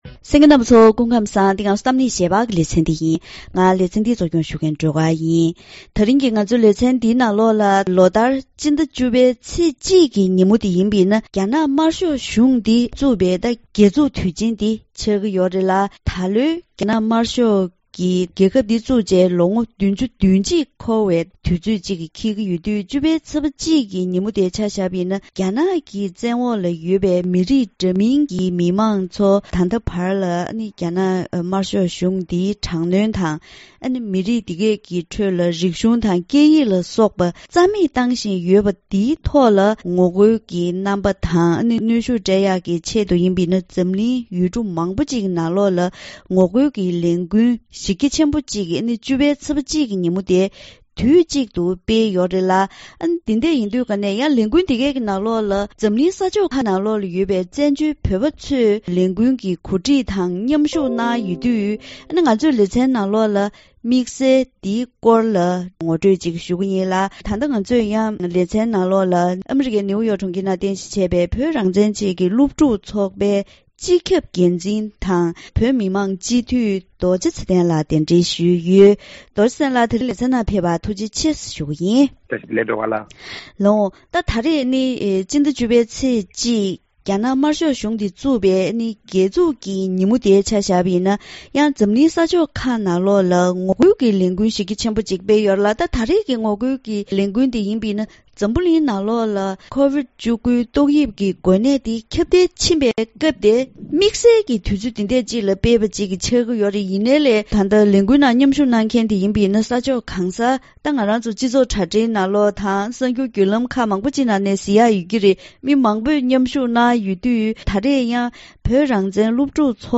ད་རིང་གི་གཏམ་གླེང་ཞལ་པར་ལེ་ཚན་ནང་རྒྱ་ནག་ལ་ངོ་རྒོལ་དང་རང་དབང་ལ་སྲུང་སྐྱོབ་ཀྱི་བརྗོད་གཞིའི་ཐོག་ནས་རྒྱ་ནག་དམར་ཤོག་གཞུང་འདི་བཙུགས་ནས་ལོ་ངོ་༧༡འཁོར་པའི་སྐབས་དེར་འཛམ་གླིང་ཡུལ་གྲུ་མང་པོའི་ནང་ངོ་རྒོལ་གྱི་ལས་འགུལ་གཞི་རྒྱ་ཆེན་པོ་ཞིག་སྤེལ་ཡོད་ཅིང་། ལས་འགུལ་ནང་བཙན་བྱོལ་དུ་ཡོད་པའི་བོད་མི་དང་རྒྱ་ནག་གི་དམངས་གཙོ་དོན་གཉེར་བ། སོག་པོ། ཡུ་གུར་བ་སོགས་ཀྱིས་མཉམ་ཞུགས་ཐོག་རྒྱ་ནག་དམར་ཤོག་གཞུང་འདིས་རང་དབང་དང་དམངས་གཙོར་ཉེན་ཁ་བཟོ་བཞིན་ཡོད་པར་ངོ་རྒོལ་བྱས་ཡོད་པའི་སྐོར་ལ་འབྲེལ་ཡོད་དང་ལྷན་དུ་གླེང་མོལ་ཞུས་པ་ཞིག་གསན་རོགས་གནང་།